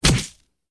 player_damage.1.ogg